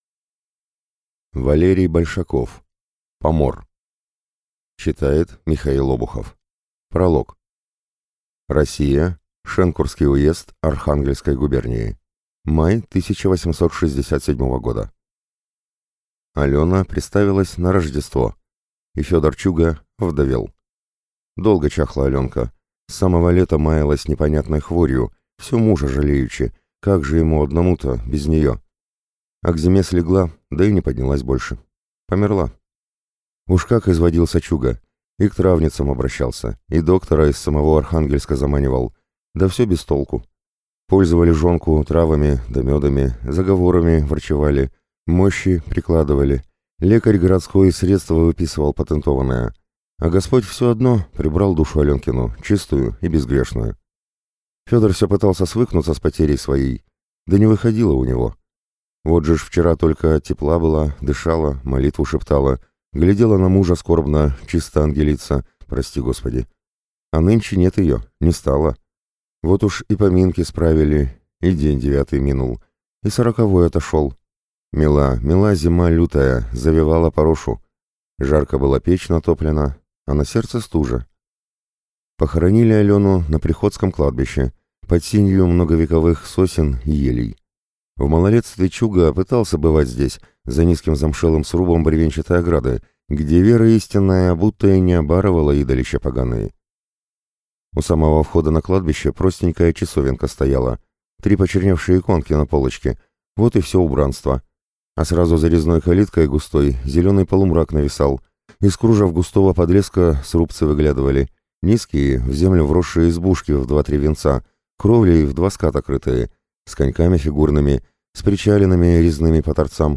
Аудиокнига Помор | Библиотека аудиокниг